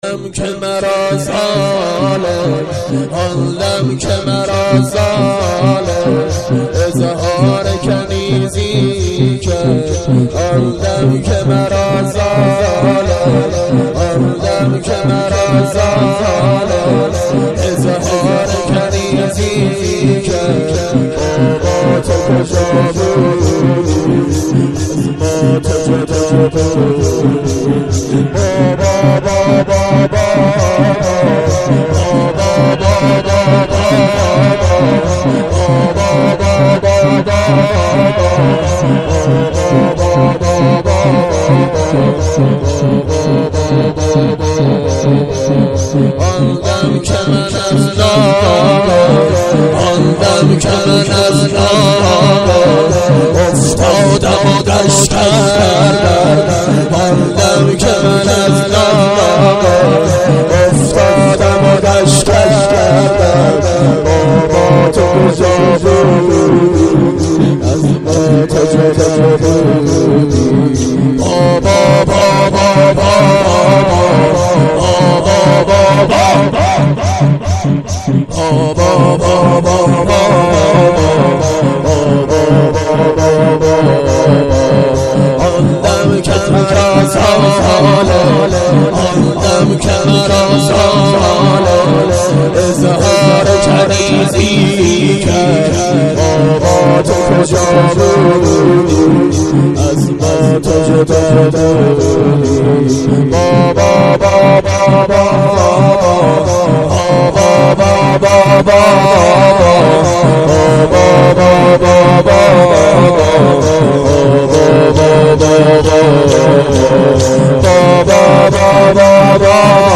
شور/آندم که مرا خواندی